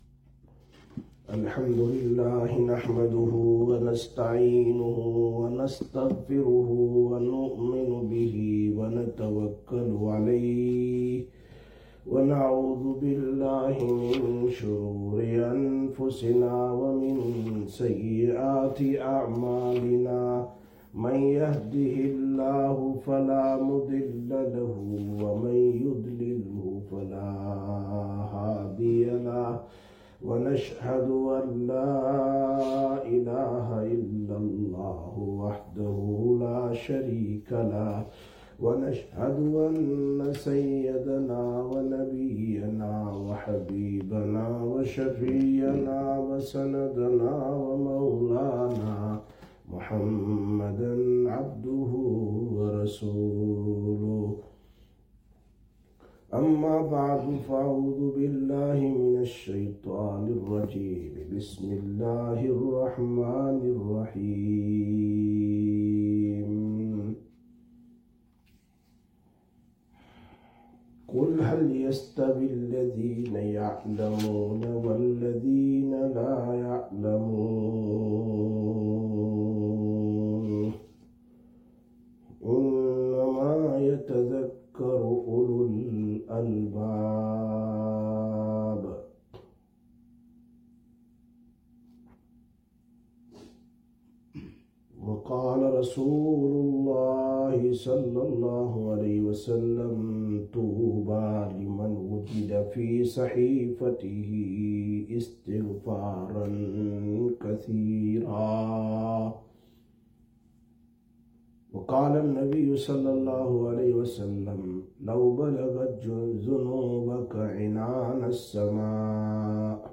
12/02/2025 Sisters Bayan, Masjid Quba